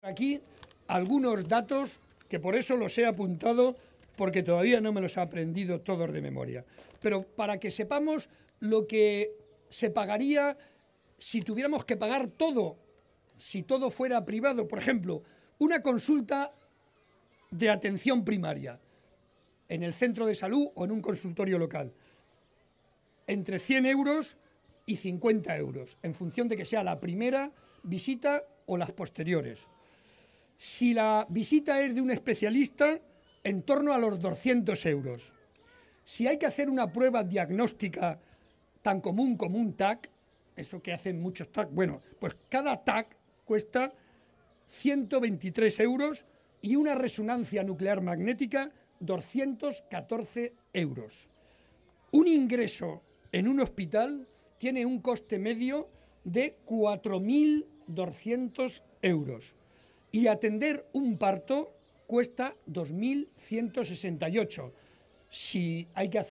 La caravana del PSOE en Fuensalida, La Puebla de Montalbán y Cebolla
Barreda hacía esta aseveración en La Puebla, arropado por más de 300 vecinos, a los que garantizó que si recibe su confianza para la Presidencia de Castilla-La Mancha los servicios públicos básicos del Estado del bienestar no verán reducido su presupuesto.